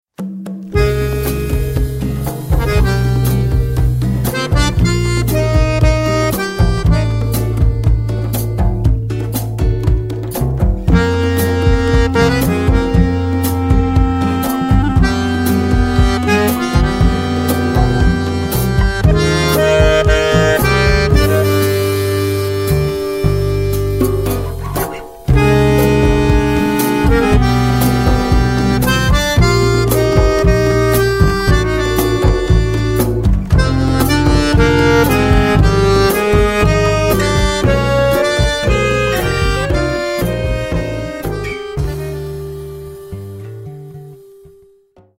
akkordeon und gesang